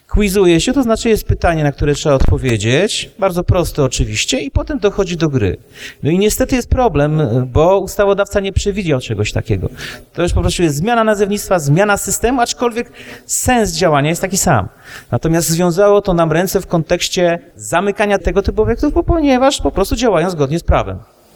Salony gier i ich legalność – to jedno z zagadnień poruszanych na 24. Sesji Rady Miejskiej w Stargardzie.
Komendant stargardzkiej policji Robert Nowak podkreślił, że producenci maszyn cały czas wynajdują sposoby na to, aby zarabiać i paradoksalnie nie łamać prawa.